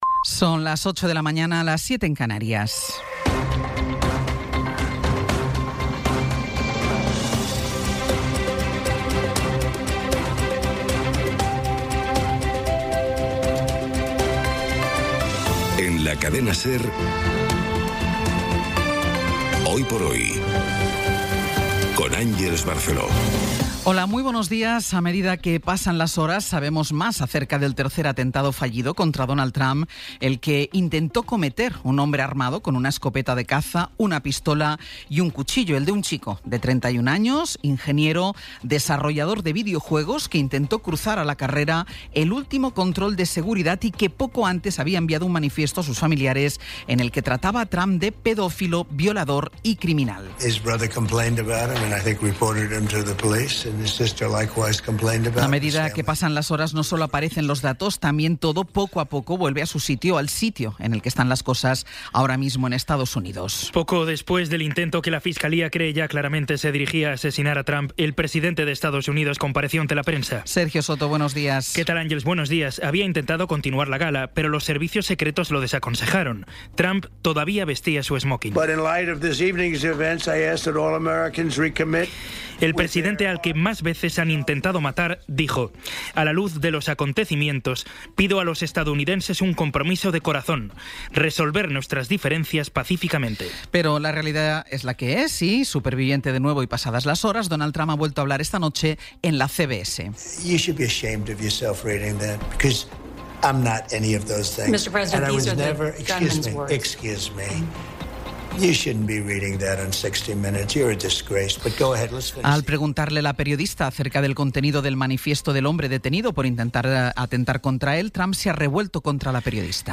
Las noticias de las 08:00 20:16 SER Podcast Resumen informativo con las noticias más destacadas del 27 de abril de 2026 a las ocho de la mañana.